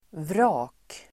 Uttal: [vra:k]